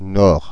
Ääntäminen
Ääntäminen France (Paris): IPA: /nɔʁ/ Paris: IPA: [nɔʁ] Haettu sana löytyi näillä lähdekielillä: ranska Käännöksiä ei löytynyt valitulle kohdekielelle.